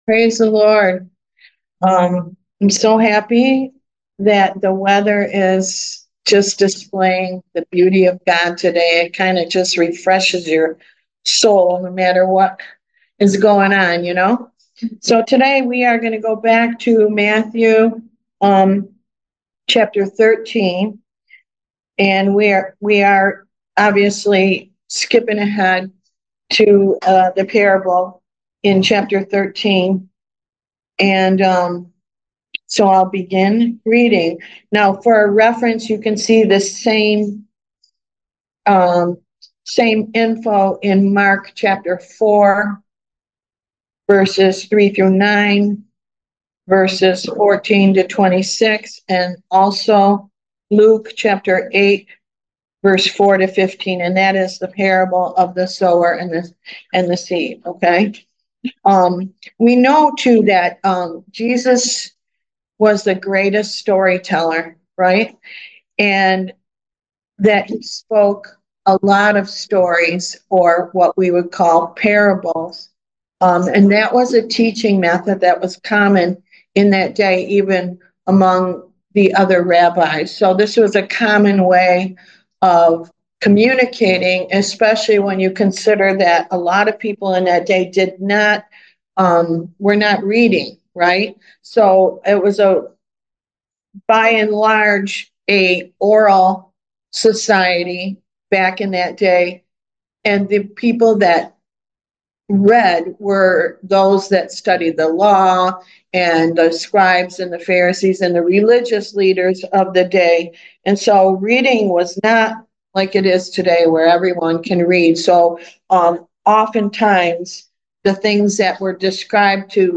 Passage: Matthew 13 Service Type: Why Jesus Class « Idolatry Kingdom Education